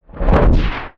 MAGIC_SPELL_Shield_mono.wav